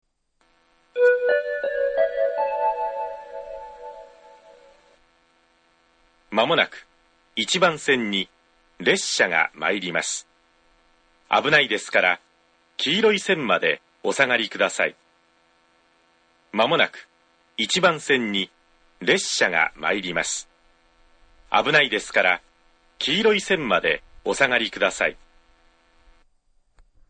iwane-1bannsenn-sekkinn.mp3